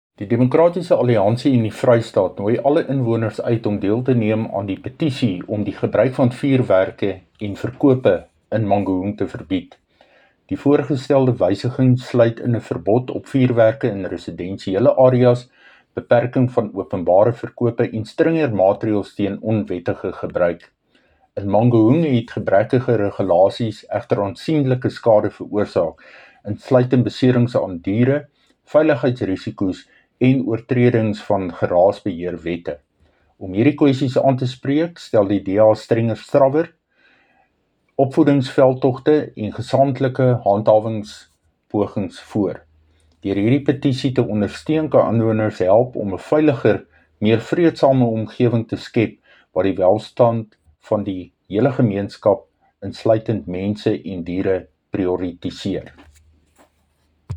Afrikaans soundbites by Cllr Dirk Kotze and